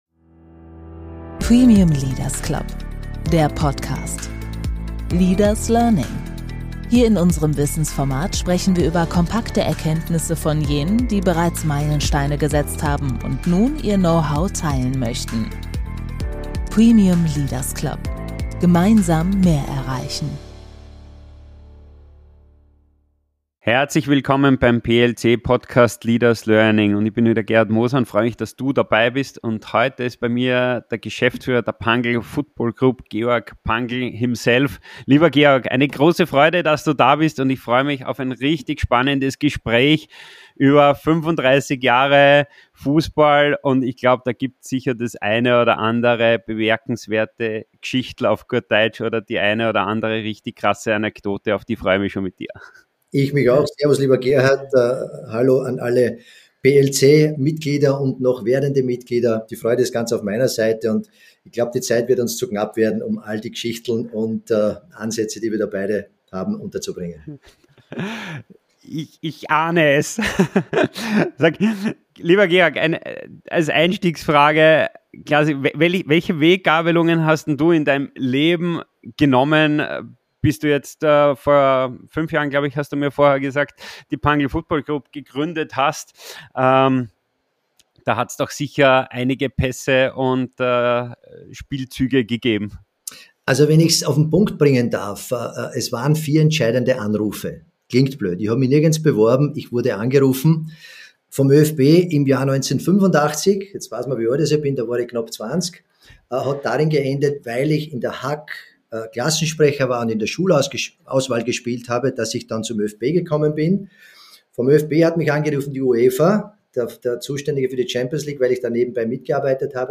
Ein tiefgründiges Gespräch über Macht und Menschlichkeit im Spitzensport, über Integration, Nachwuchsarbeit, nachhaltige Verantwortung – und über Geschichten, die man nie googeln kann.